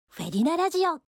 ②やや早口、スタイリッシュ風